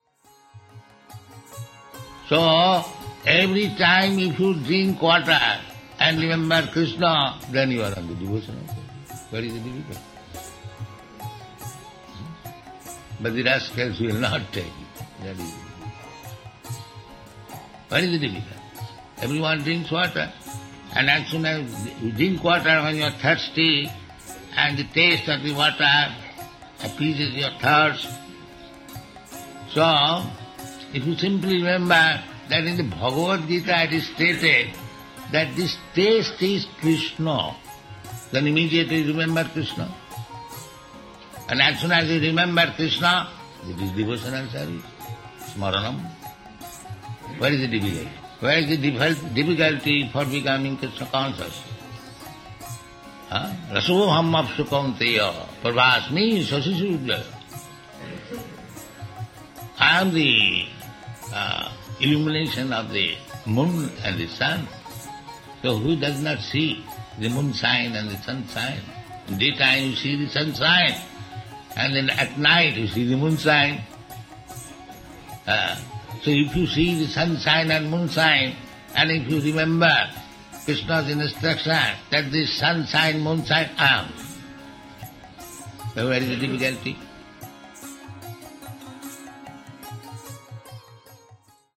(760316 – Lecture Festival SB 07.09.38 Gaura-Purnima – Mayapur)